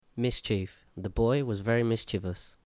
7 Mischief  ˈmɪstiːf